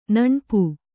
♪ naṇpu